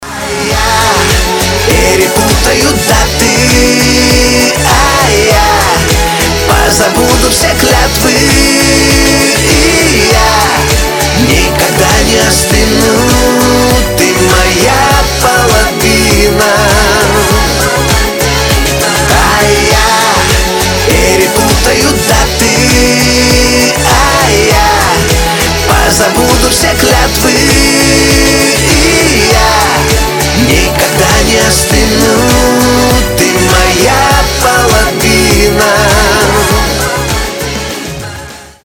эстрадные
русская эстрада